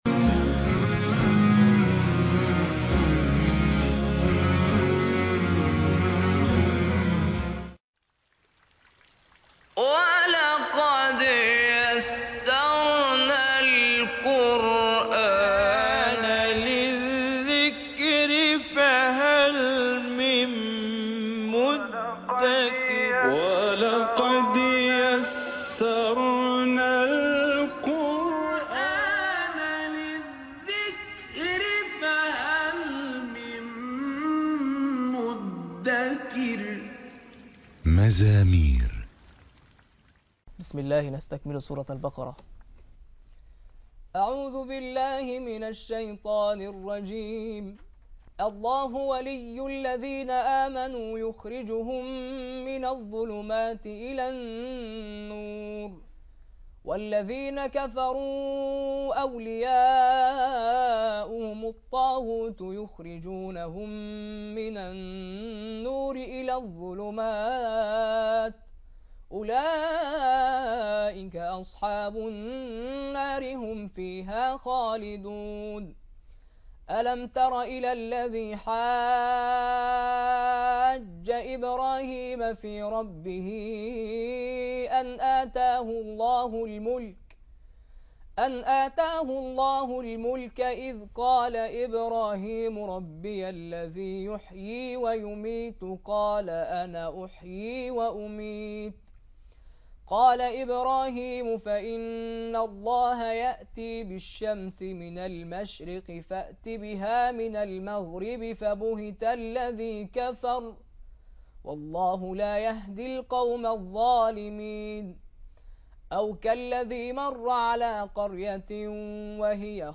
ورد سورة البقرة بتلاوة خاشعة حلقة 09 مزامير مجموعة من القراء - قسم المنوعات